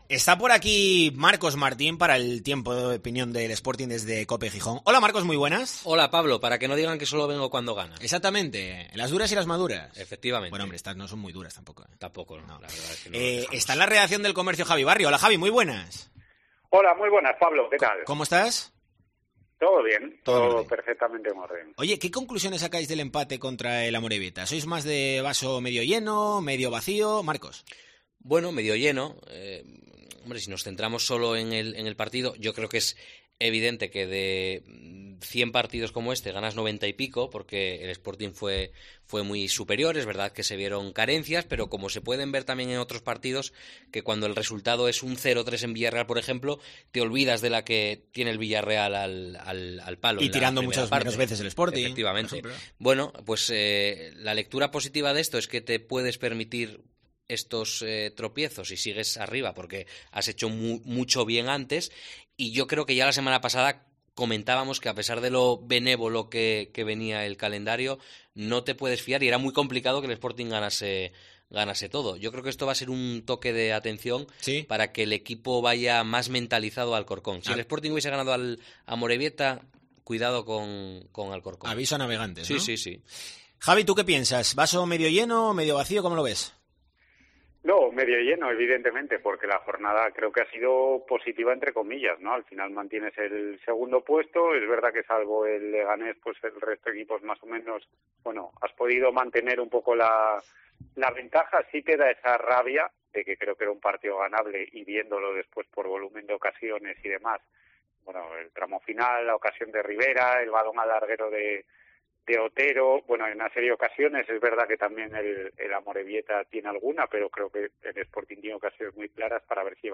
OPINION COPE ASTURIAS